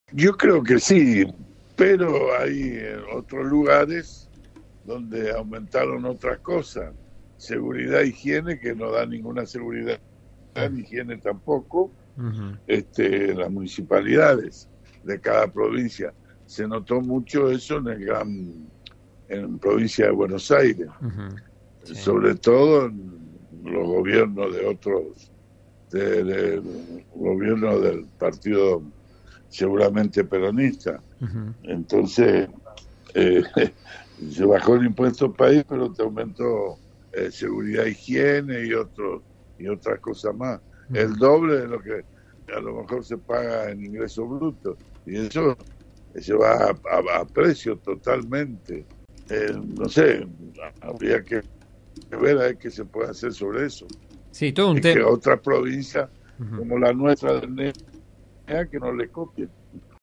En una entrevista con el programa Plan B en Radio